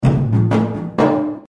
描述：一个传统的Boduberu节拍。这种风格通常由三面鼓演奏，这面鼓作为主拍，其他两面鼓演奏变化。
标签： 126 bpm Ethnic Loops Percussion Loops 497.79 KB wav Key : Unknown
声道立体声